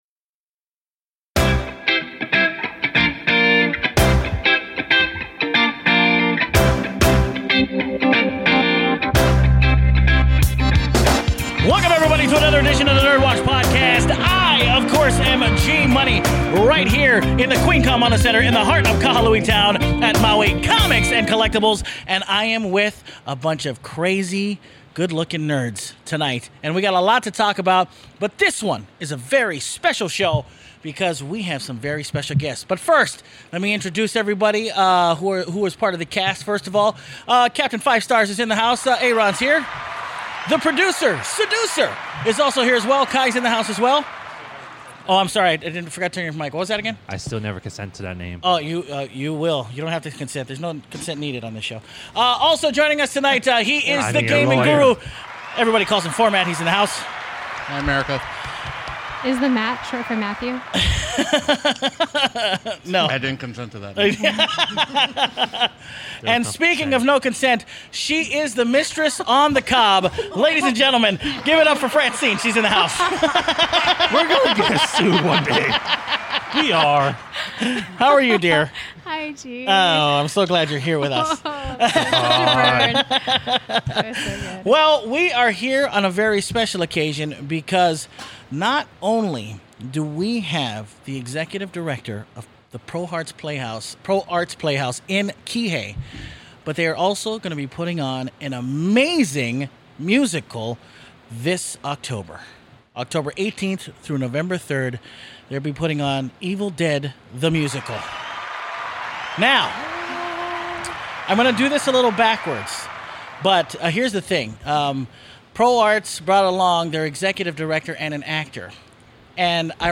ProArts Maui's Evil Dead Musical Interview